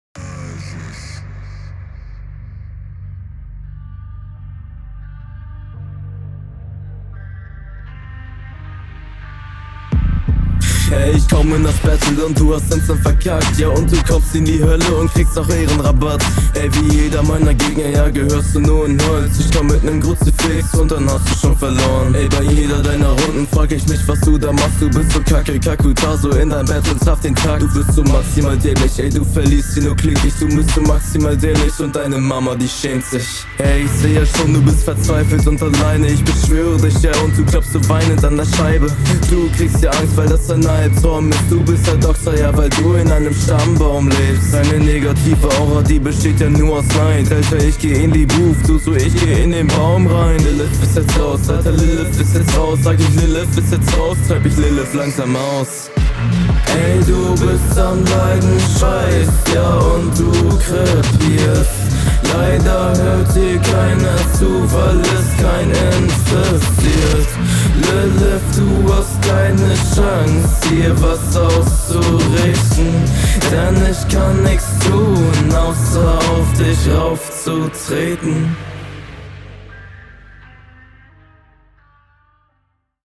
Ziemlich stabil gerappt mit teils variiert gesetzten Betonungen, auch wenn sie grundsätzlich in einer tiefen, …